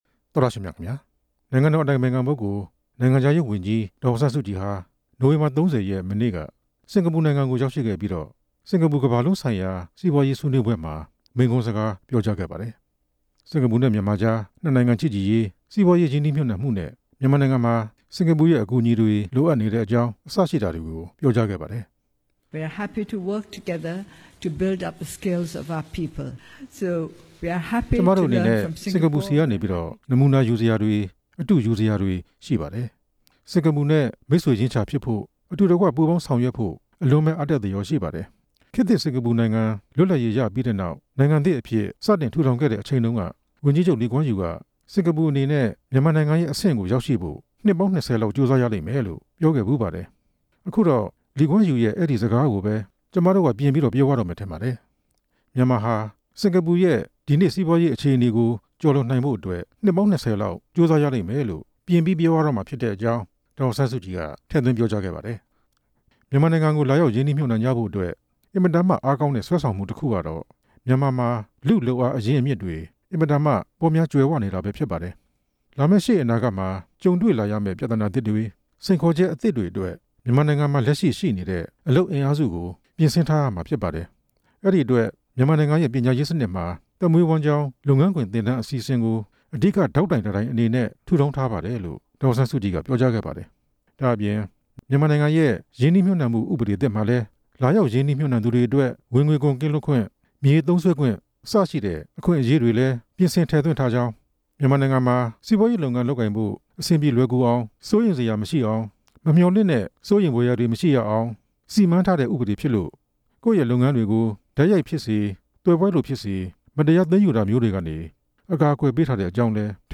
စင်္ကာပူ စီးပွားရေးဆွေးနွေးပွဲ က ဒေါ်အောင်ဆန်းစုကြည် မိန့်ခွန်း ကောက်နှုတ်ချက်
နိုင်ငံခြားရေးဝန်ကြီး ဒေါ်အောင်ဆန်းစုကြည်ဟာ စင်္ကာပူ စီးပွားရေးဆွေးနွေးပွဲကို တက်ရောက်ပြီး၊ မိန့်ခွန်းစကားပြောကြားခဲ့ပါတယ်။